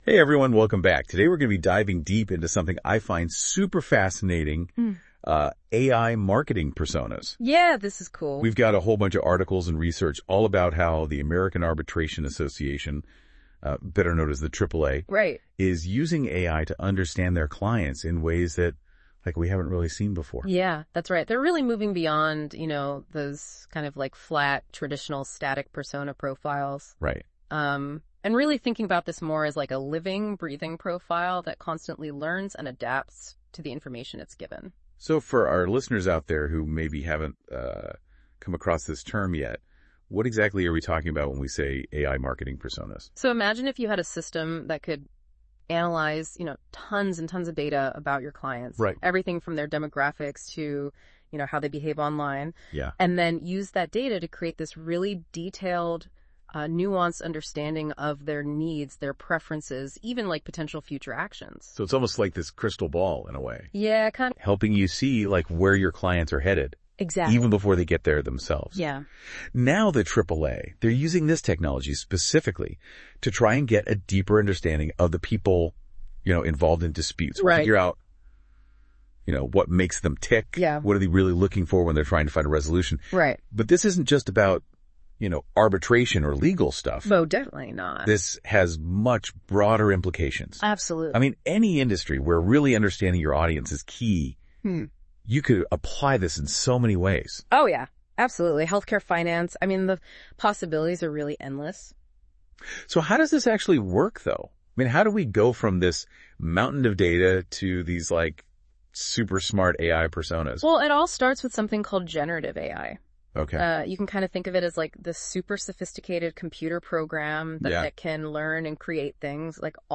A Deeper Understanding of Clients through AI: A Conversation